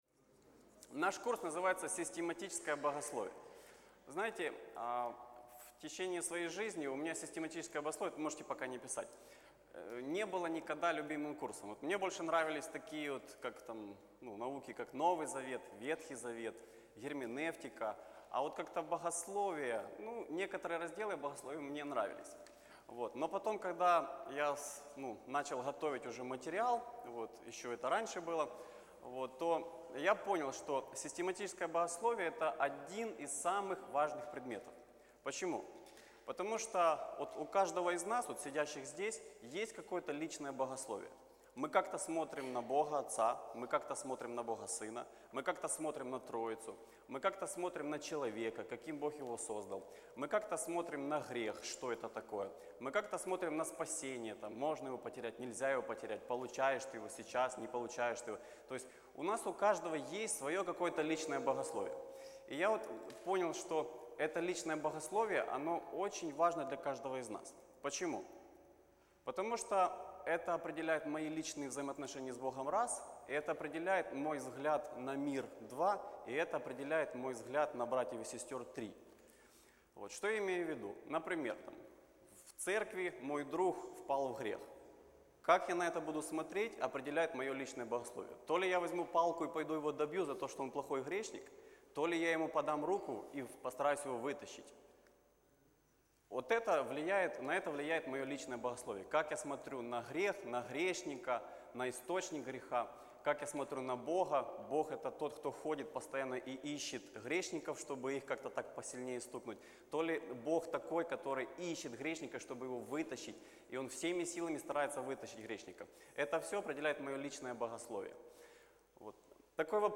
Лекция 1.mp3